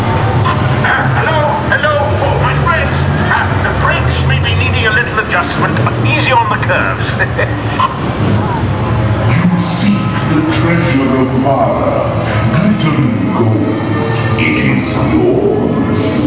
Sound you may hear as you begin your journey into the temple of the forbidden eye.